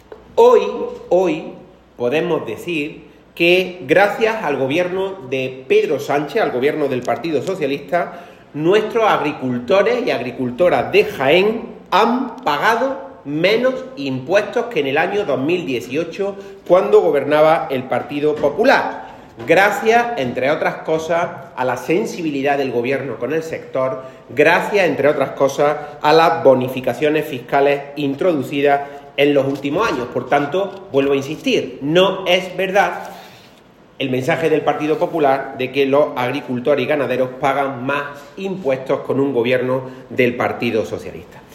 En rueda de prensa, Latorre puso ejemplos concretos que demuestran su afirmación y que contradicen los bulos propagados por el PP y alguno de sus alcaldes.
Cortes de sonido